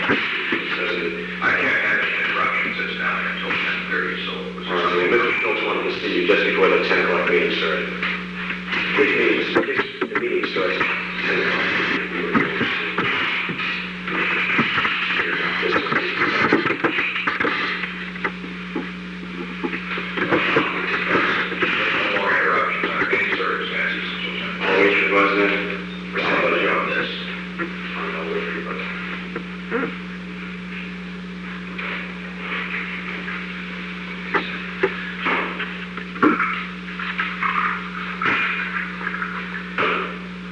Secret White House Tapes
Conversation No. 538-7
Location: Oval Office